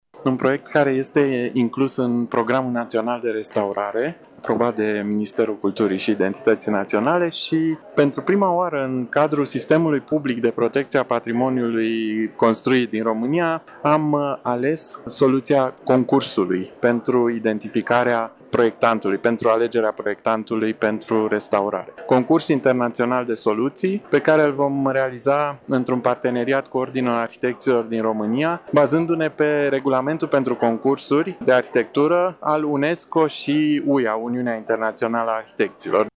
Procedura de atribuire a fost lansată de Institutul Naţional al Patrimoniului, iar fondurile pentru finanțarea lucrării vor veni prin Programul Național de Restaurare, spune directorul general al Institutului National al Patrimoniului, Ștefan Bâlici: